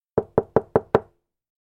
جلوه های صوتی
دانلود صدای در زدن از ساعد نیوز با لینک مستقیم و کیفیت بالا